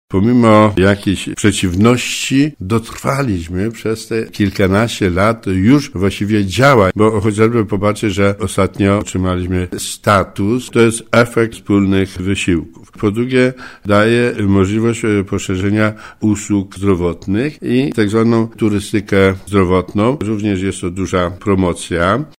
Jak mówi wójt gminy Dębica Stanisław Rokosz warto było czekać na ten moment tyle lat.